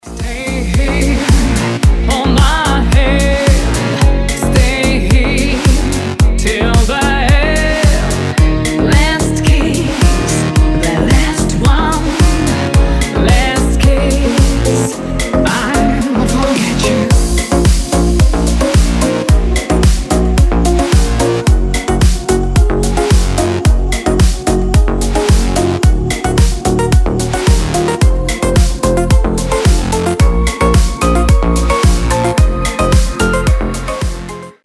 • Качество: 320, Stereo
женский вокал
deep house
чувственные
nu disco
remastered
Ремастеринг песни 2015 года.